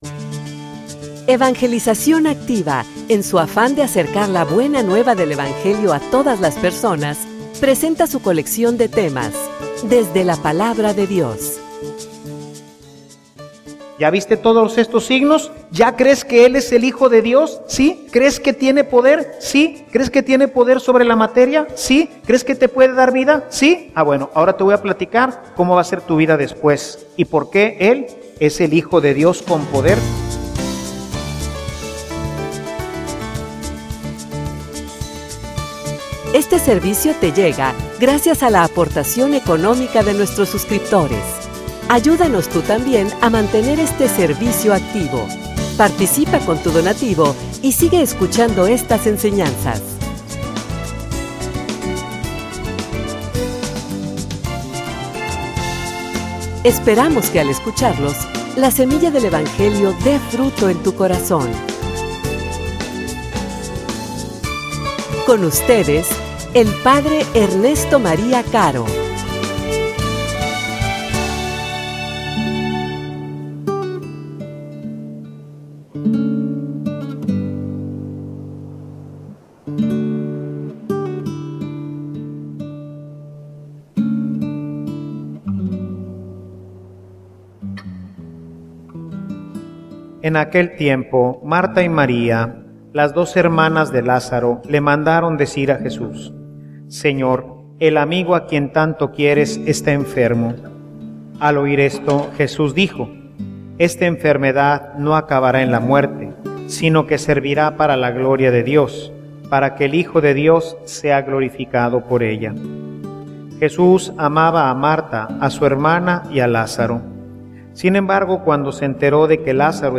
homilia_Crees_tu_esto.mp3